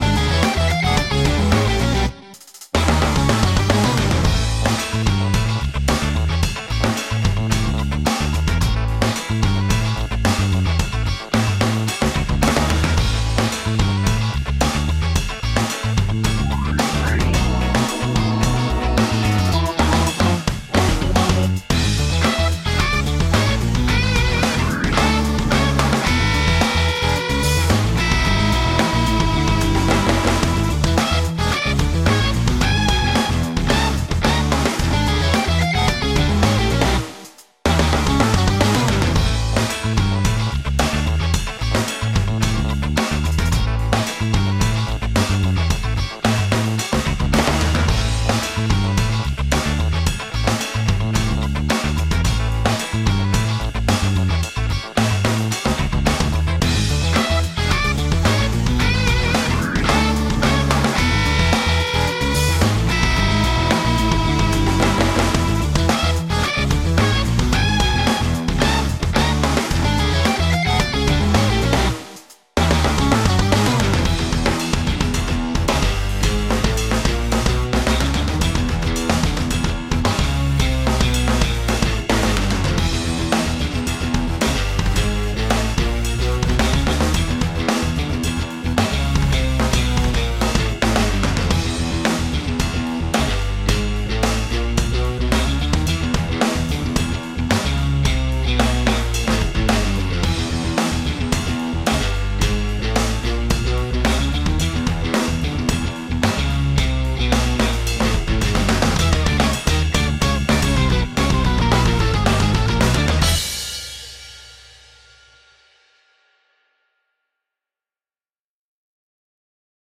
Konkursowe podkłady muzyczne:
PODKŁAD 4.